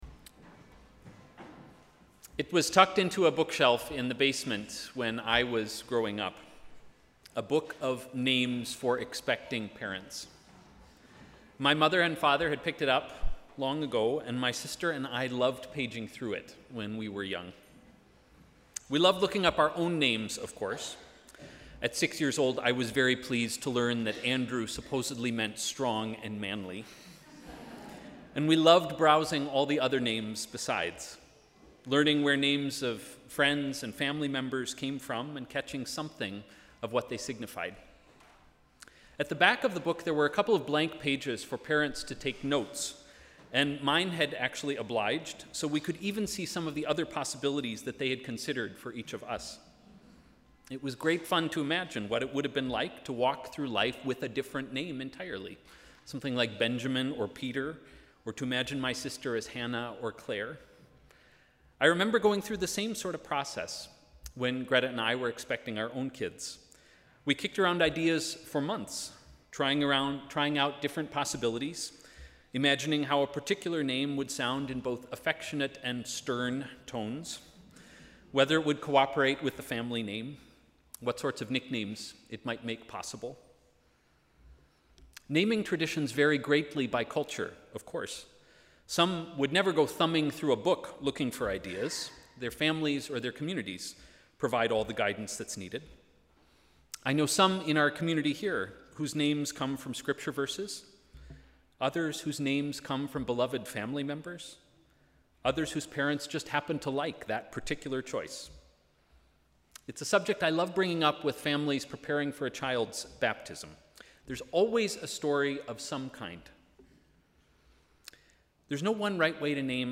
Sermon: ‘Jesus, Emmanuel’